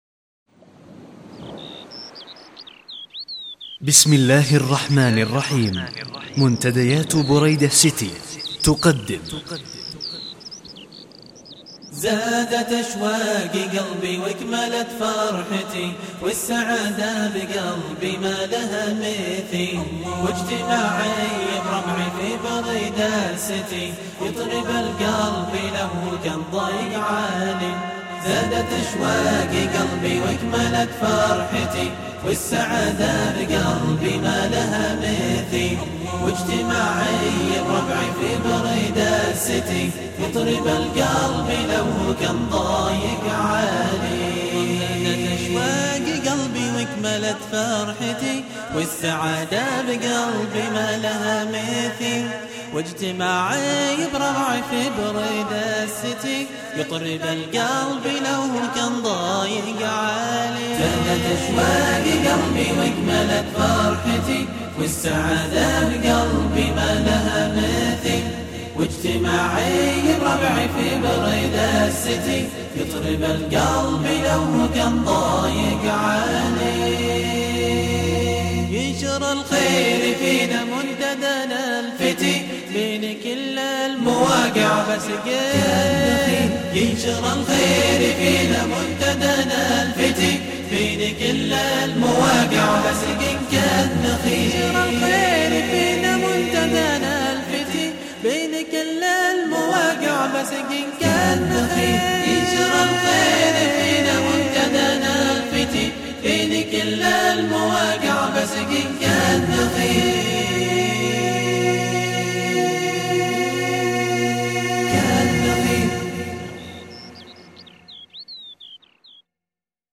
أنشوده رائعه جدا ..